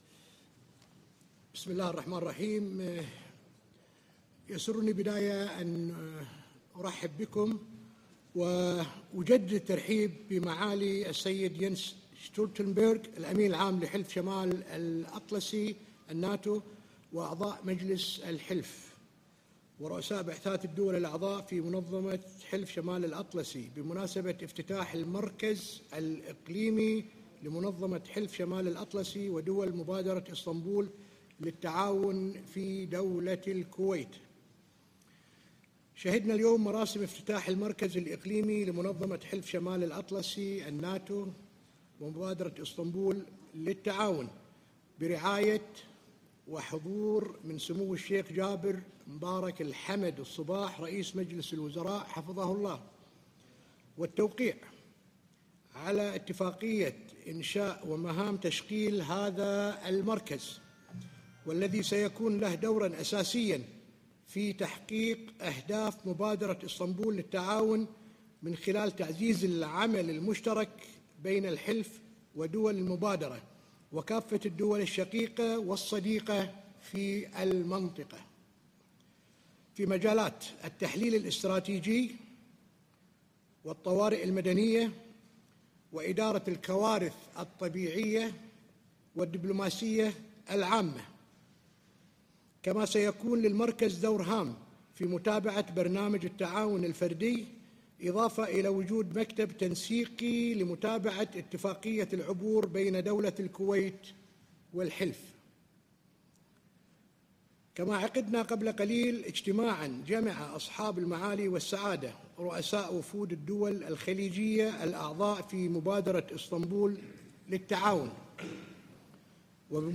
Joint press conference with NATO Secretary General Jens Stoltenberg and the Deputy Prime Minister/Foreign Minister of Kuwait, Sheikh Sabah Khaled Al-Hamad Al-Sabah